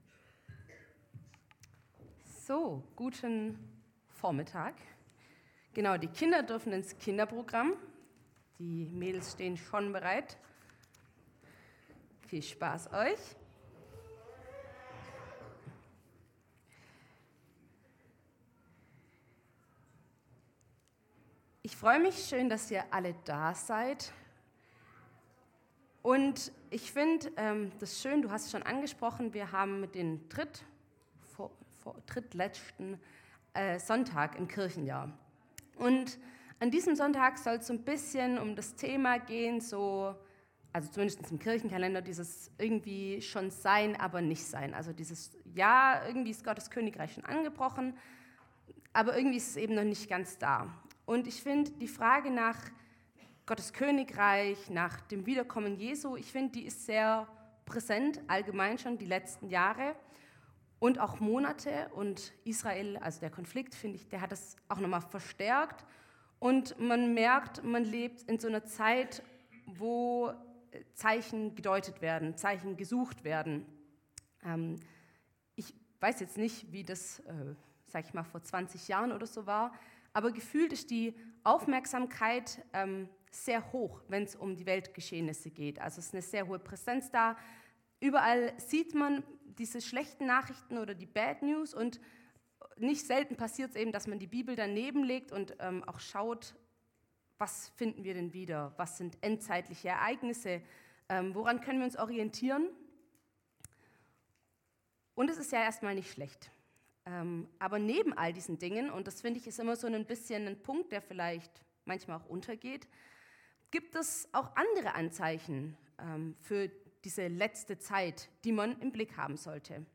Predigt am 12.11.2023